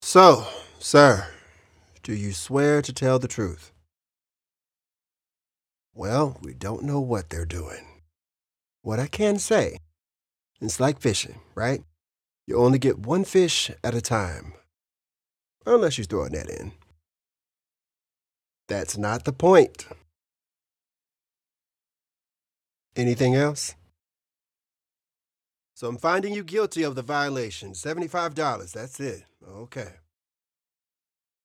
Professional voice actor, vocalist, and recording artist with a warm, grounded delivery and strong long-form consistency.
General American, Neutral US
Middle Aged
I am a full-time voice actor and professional vocalist working from a broadcast-quality home studio.